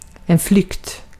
Uttal
Synonymer rymning Uttal Okänd accent: IPA: /flʏkt/ Ordet hittades på dessa språk: svenska Ingen översättning hittades i den valda målspråket.